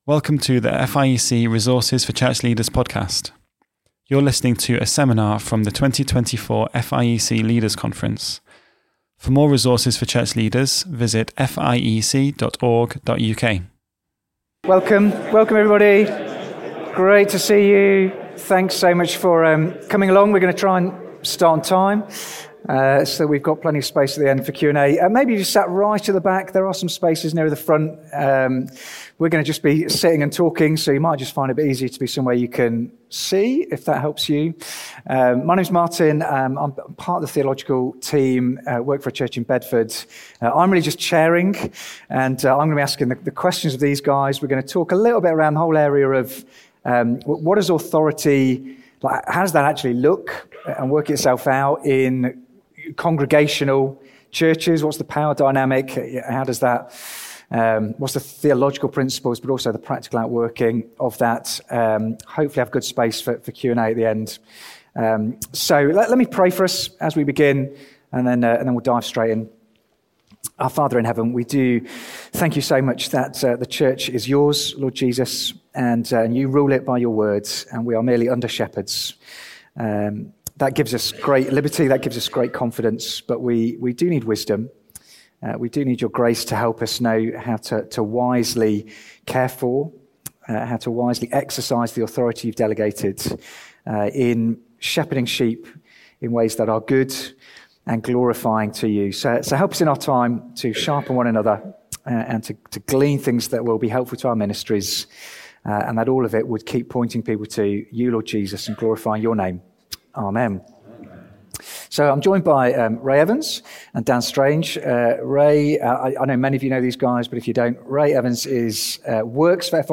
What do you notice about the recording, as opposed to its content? How can we exercise authority in ways which bless the church and cause it to flourish? A seminar from the 2024 Leaders' Conference.